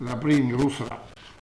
Patois - ambiance
Catégorie Locution